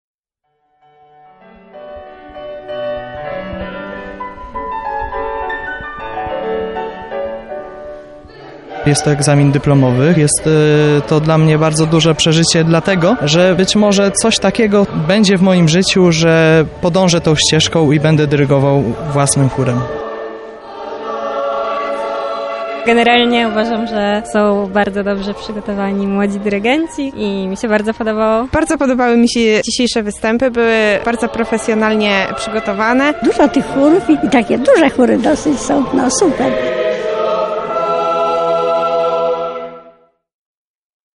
Koncert dyplomowy odbył się w kościele Garnizonowym w Lublinie.